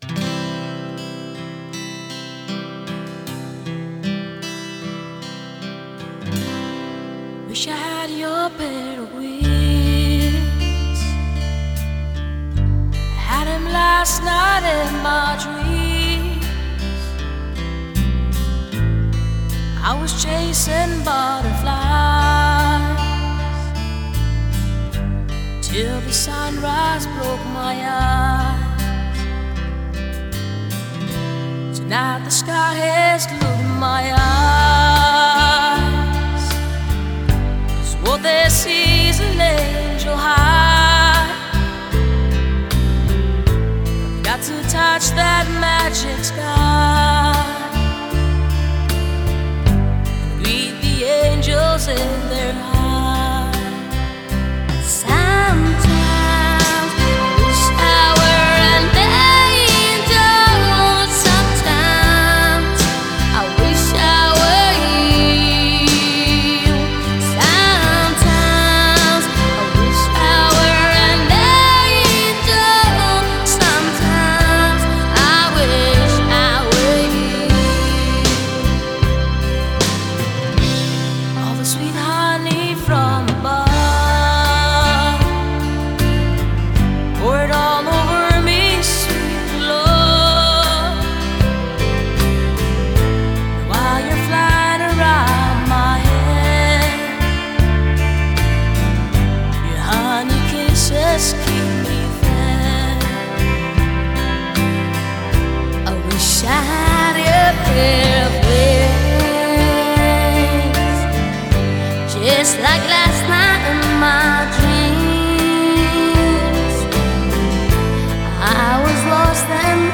европейская фолк/рок группа